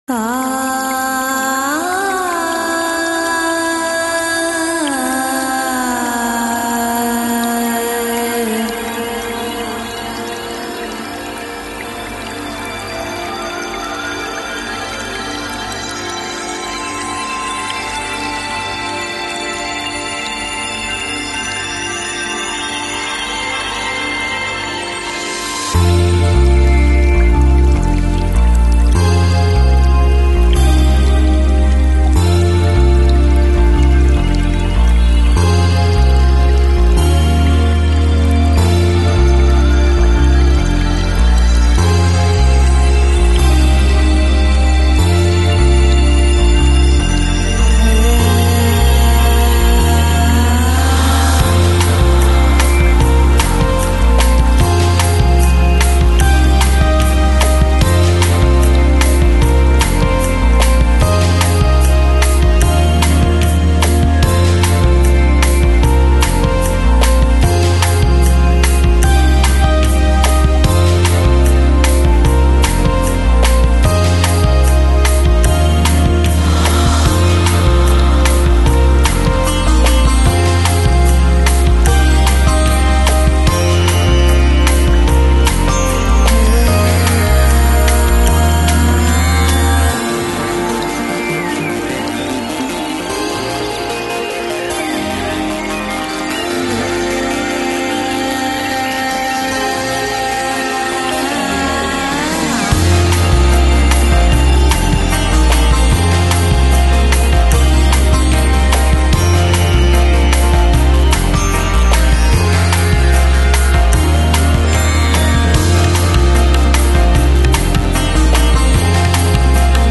Chill Out, Lounge, Downtempo Носитель